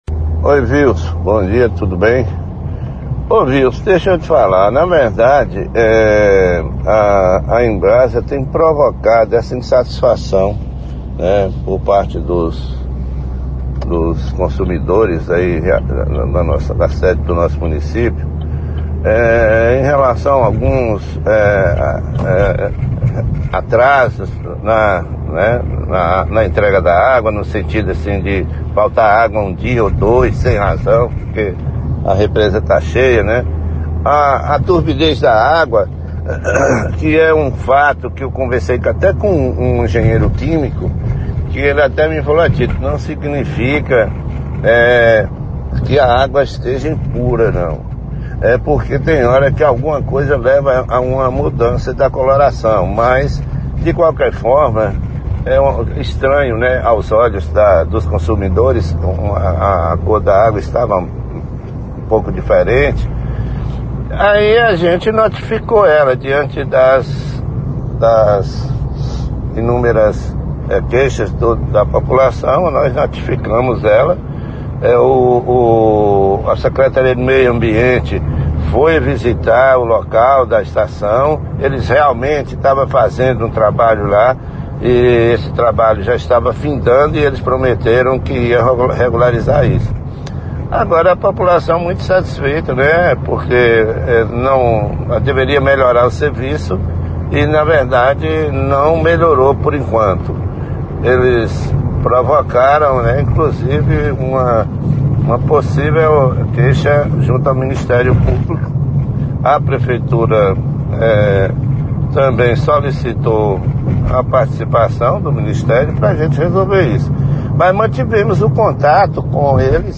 O site manteve contato com o Prefeito Municipal e o Secretário de Meio Ambiente, que cientes da situação, informaram que a Embasa já foi notificada a prestar esclarecimentos em mais de uma oportunidade e não havendo resposta recorreram ao Ministério Público solicitando audiência pública que possa resolver a demanda.